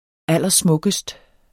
Udtale [ ˈalˀʌˈsmɔgəsd ]